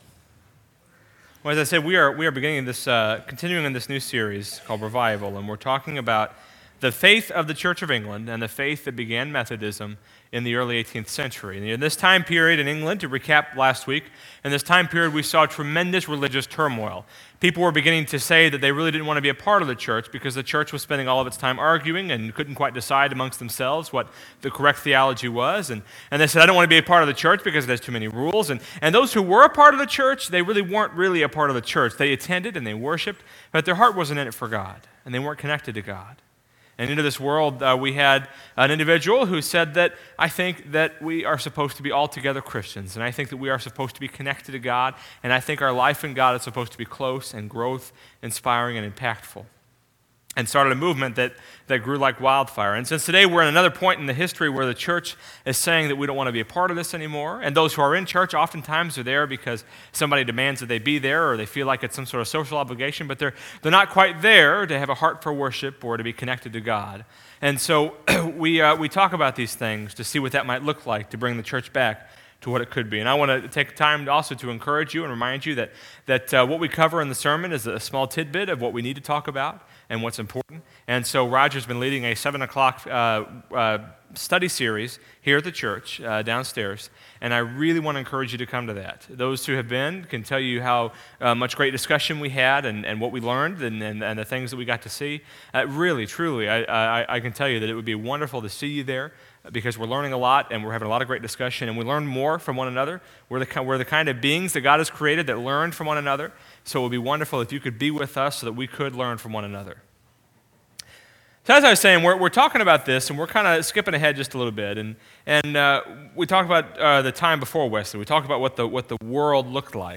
Service Type: Sunday Morning
Sermon-9-21-14.mp3